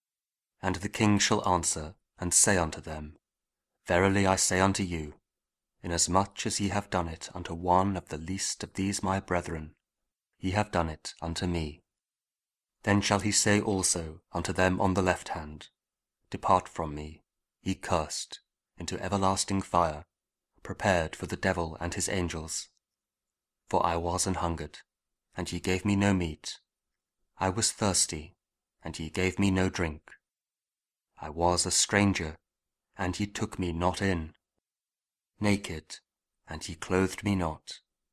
Gospel Reading: Matthew 25:40 (KJV)